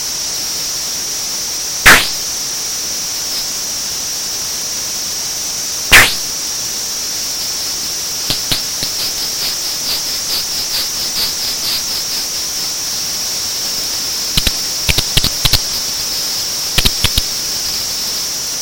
switch scratching keyboard
switch-scratching-keyboard.mp3